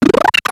Cri de Poichigeon dans Pokémon X et Y.